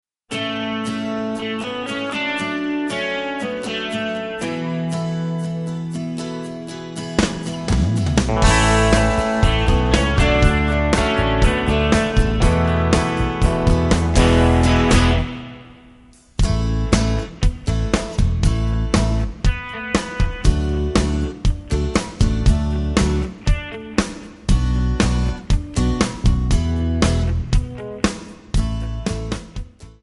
Backing track Karaokes